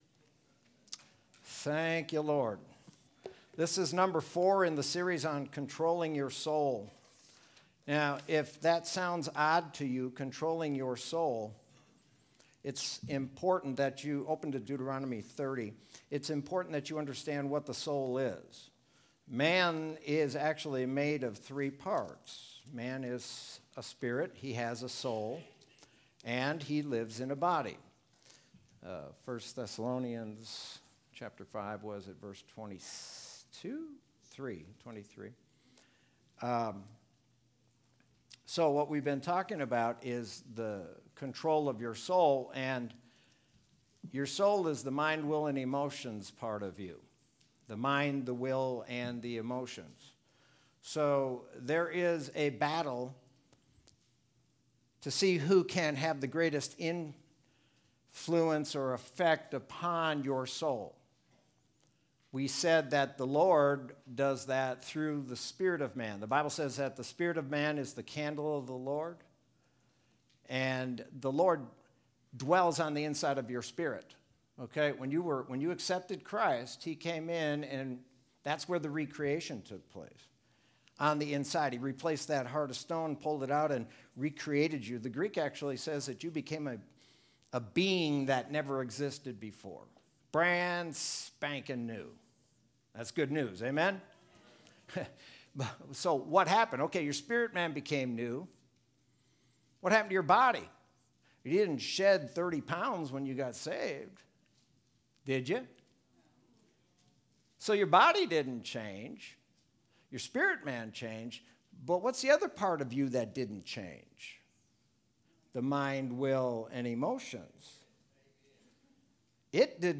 Sermon from Sunday, August 16th, 2020.